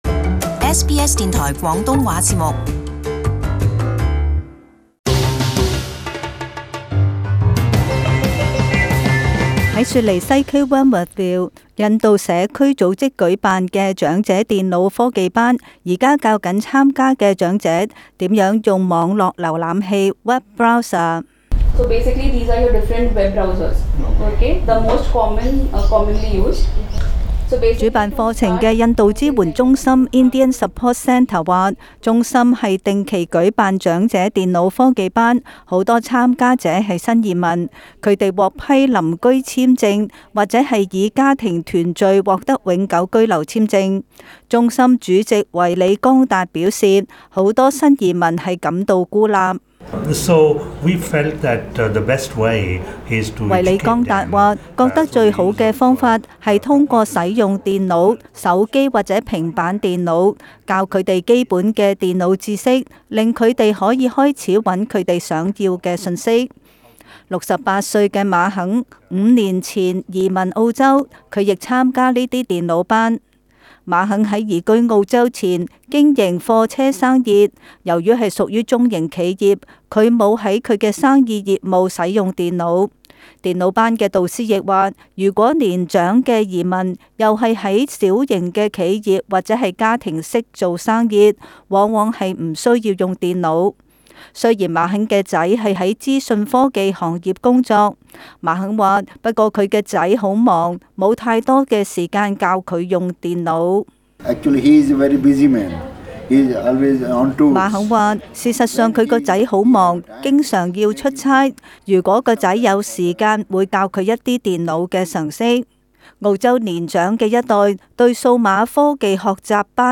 【時事報導】長者電腦班